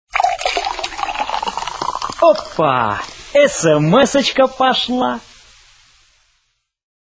Звуки СМС
Звуковой сигнал для оповещения о смс в телефоне (смсочка пошла)